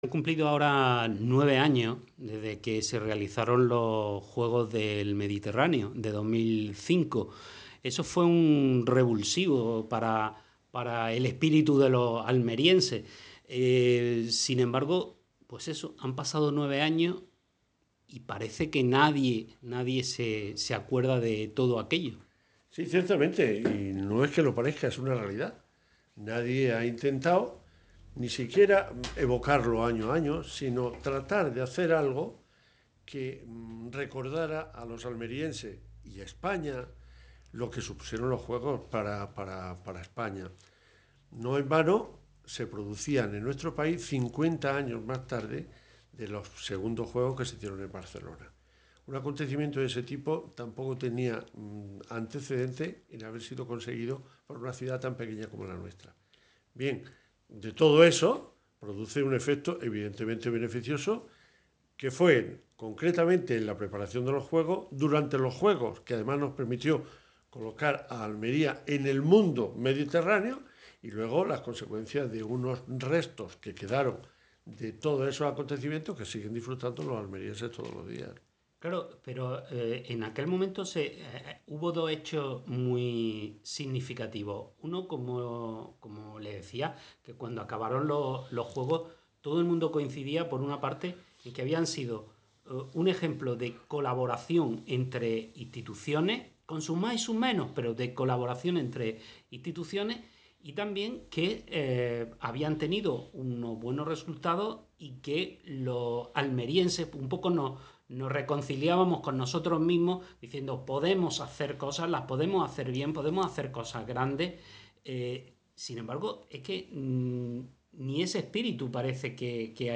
meginoentrevista.mp3